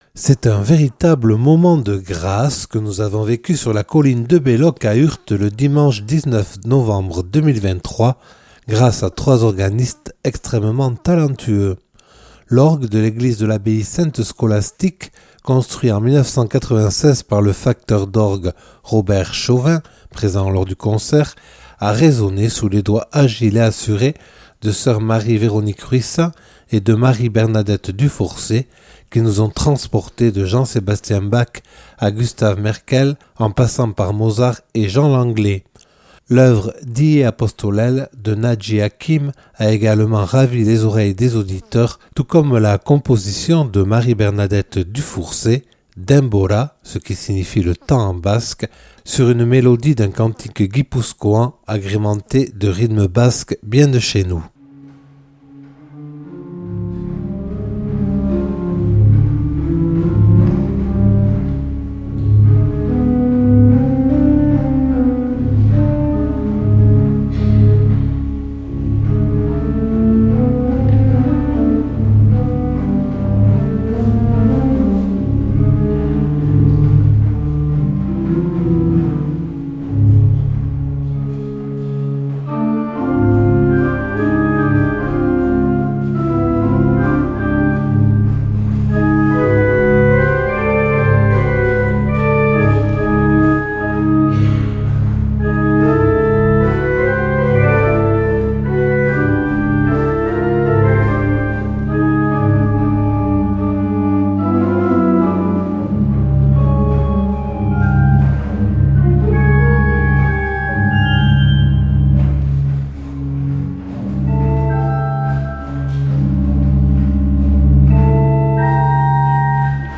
C’est un véritable moment de grâce que nous avons vécu sur la colline de Belloc à Urt le dimanche 19 novembre 2023 grâce à trois organistes extrêmement talentueux.